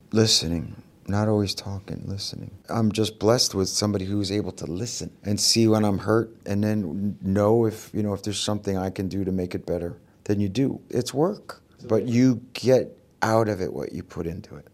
SWR3 exklusiv: Interview mit Jon Bon Jovi